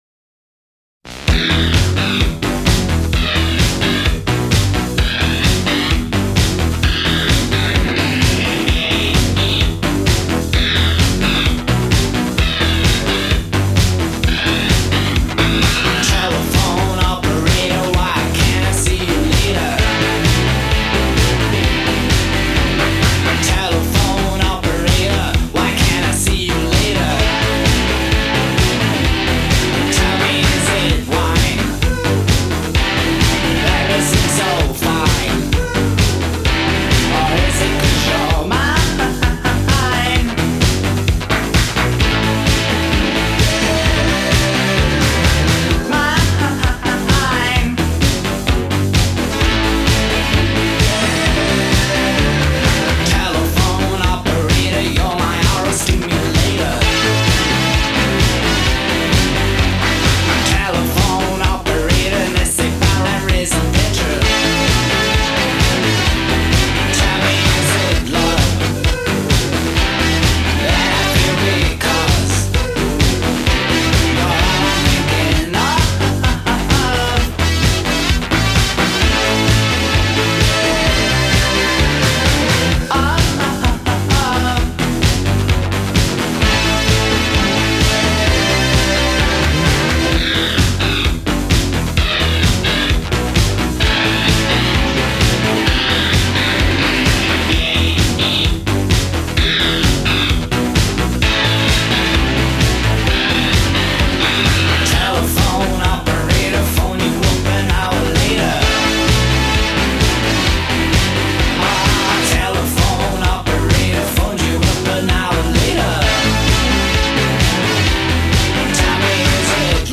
post punk
a nagging riff (played on synth rather than guitar)